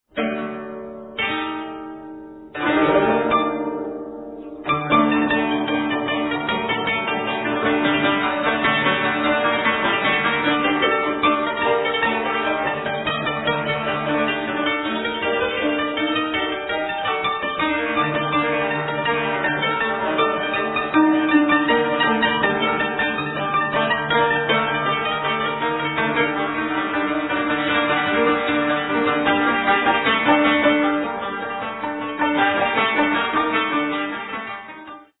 folk melody